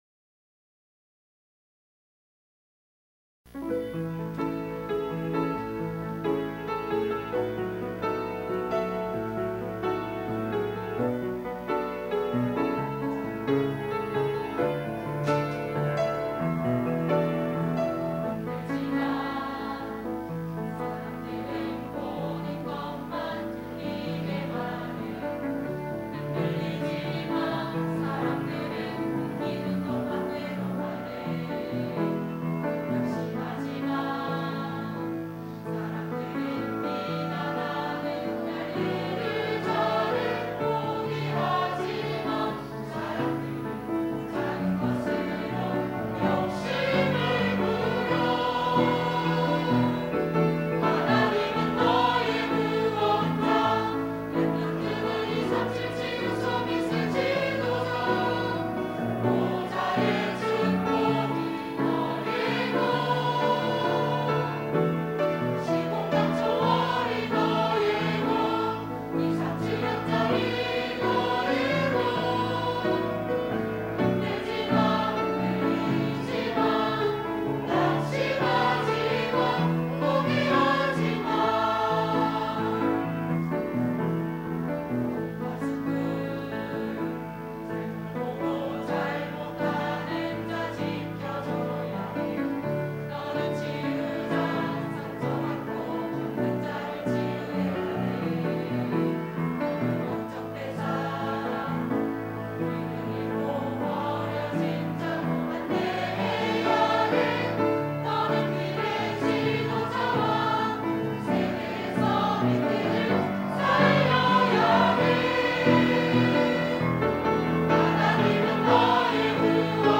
3부 찬양대(~2024)